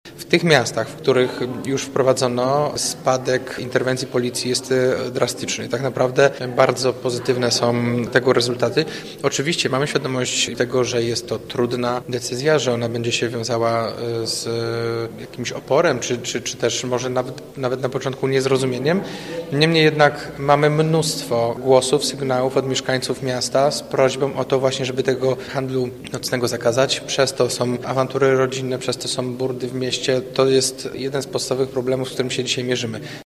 – Pomysł sprawdziły już inne polski miasta – mówi prezydent Gorzowa Jacek Wójcicki: